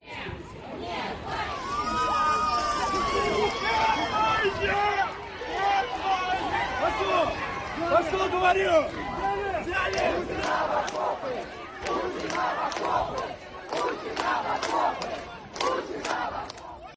Las protestas contra la movilización de reservistas en Rusia deja sonidos como estos
Los congregados gritaron "no a la guerra" entre aplausos y "Putin a la trinchera".
Otros coreaban "la Policía es la vergüenza de Rusia".